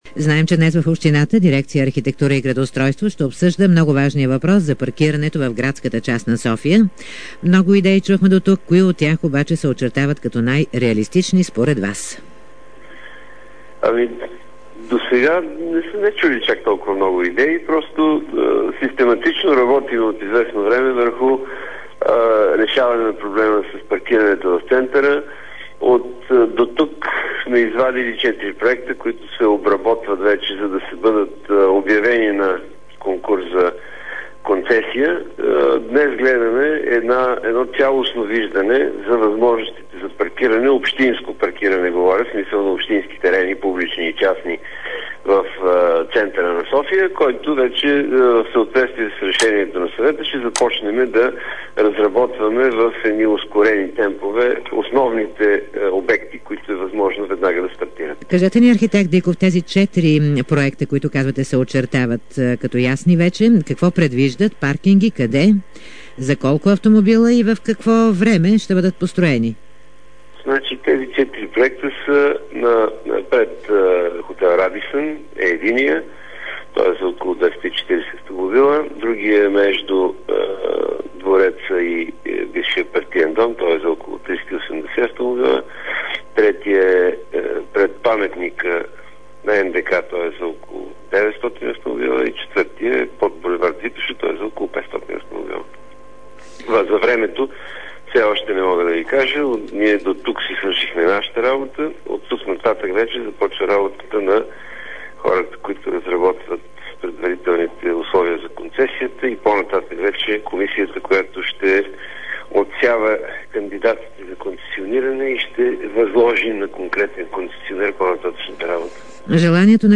DarikNews audio:Интервю